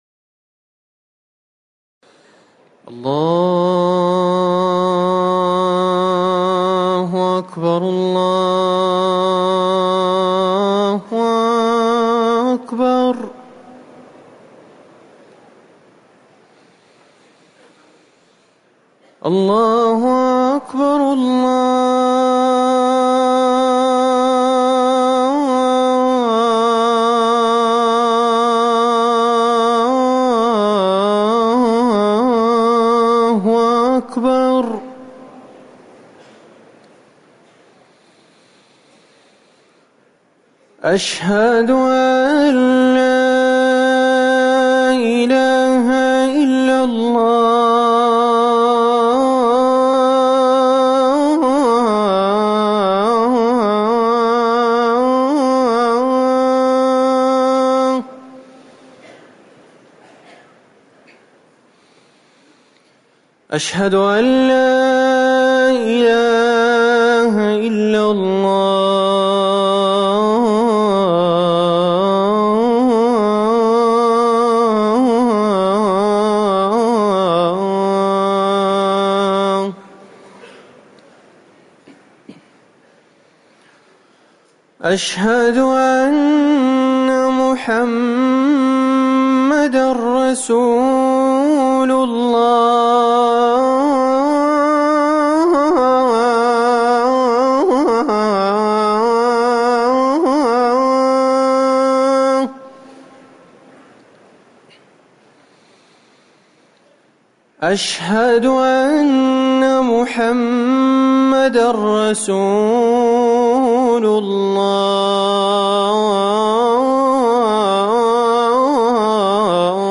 أذان الظهر - الموقع الرسمي لرئاسة الشؤون الدينية بالمسجد النبوي والمسجد الحرام
تاريخ النشر ٩ محرم ١٤٤١ هـ المكان: المسجد النبوي الشيخ